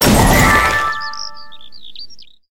sprout_atk_ulti_explo_02.ogg